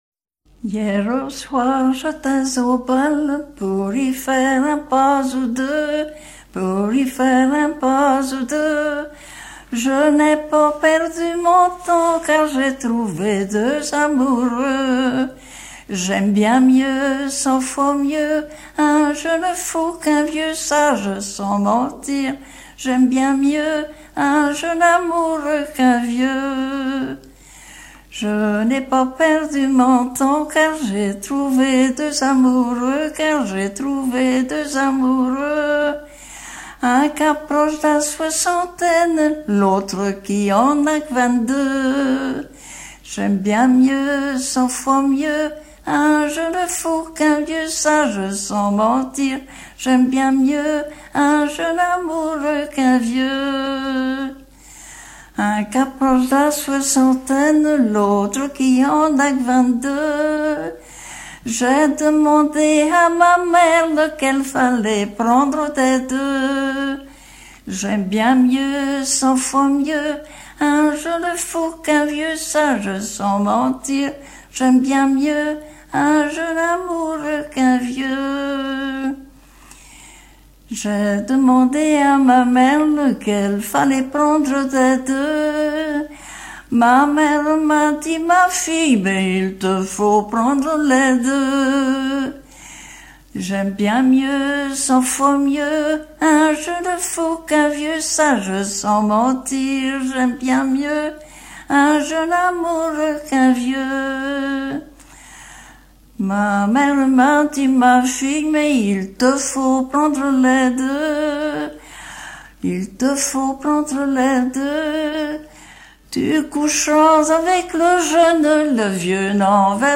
Chansons en dansant
Genre laisse